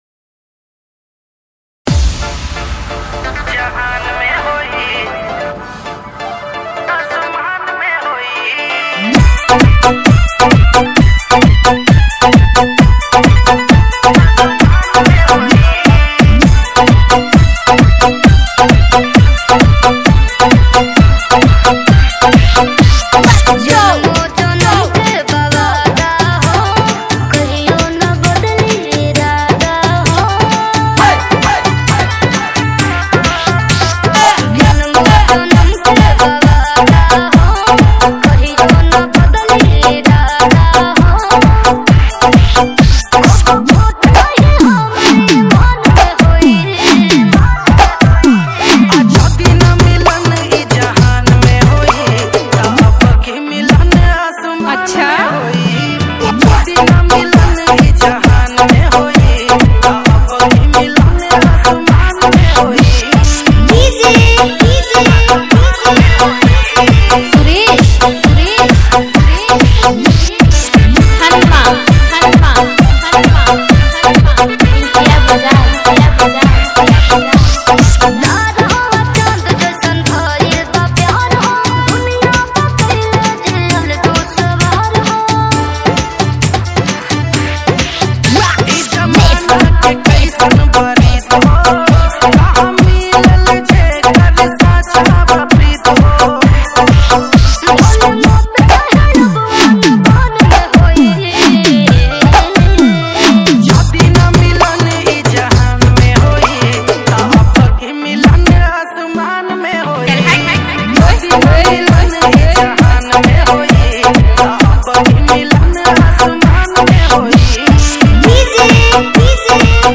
Bhojpuri Sad DJ Remix